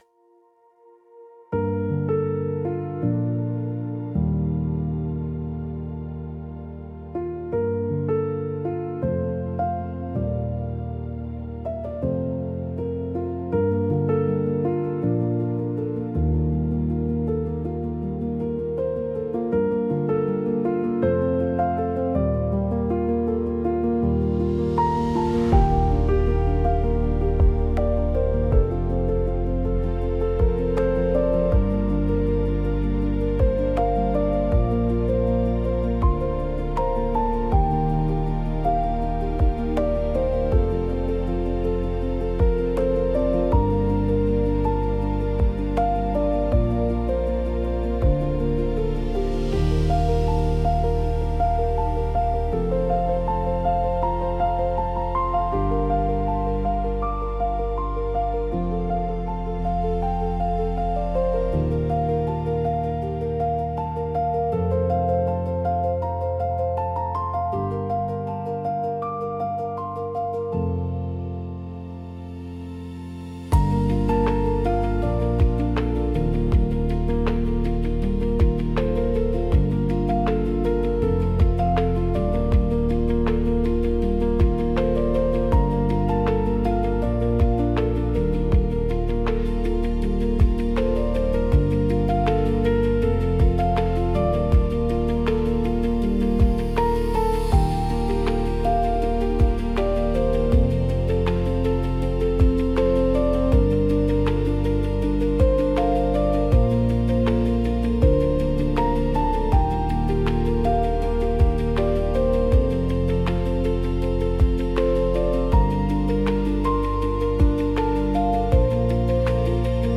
Instrumental: (Remastered)